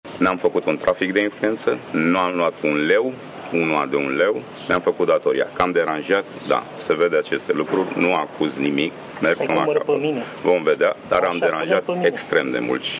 „Că am deranjat? Da. Se vede acest lucru. Nu acuz nimic, merg până la capăt. Vom vedea, dar am deranjat extrem de mult” a declarat șeful ANPC .